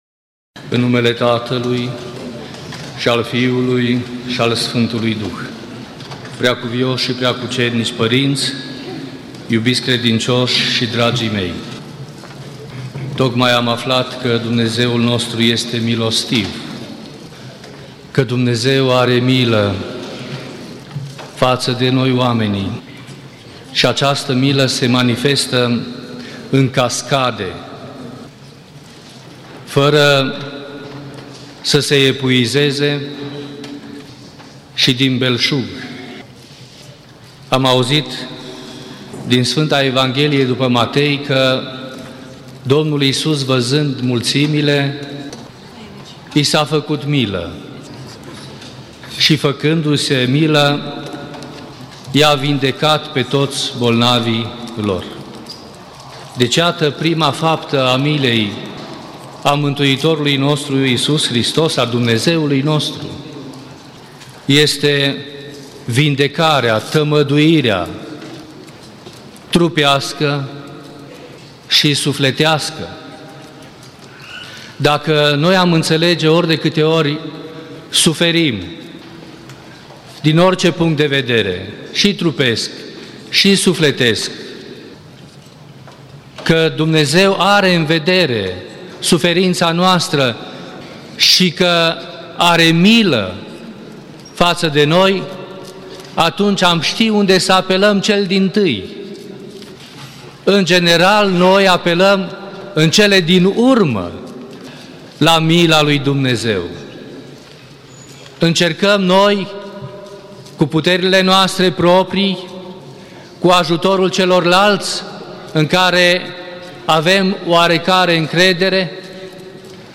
Predică la Duminica a 8-a după Rusalii (Înmulțirea pâinilor)